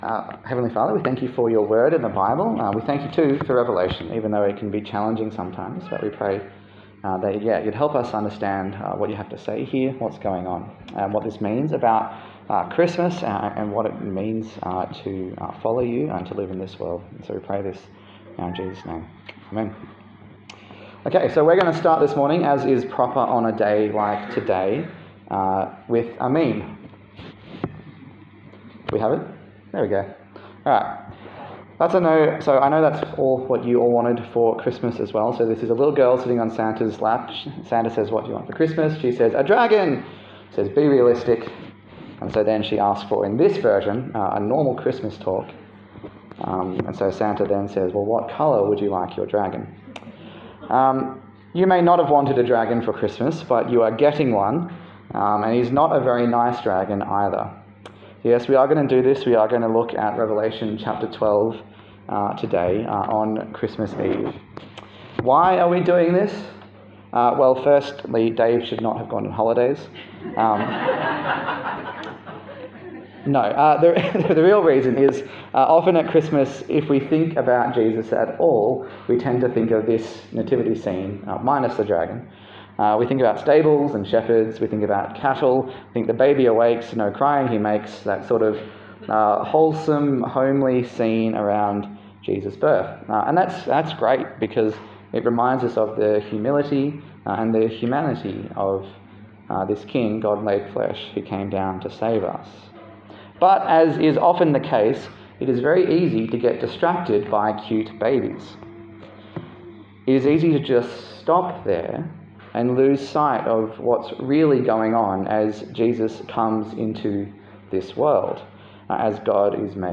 Service Type: Christmas Eve